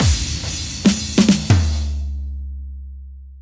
VTDS2 Song Kit 13 Female Play Girl Fill.wav